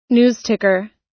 News_ticker.wav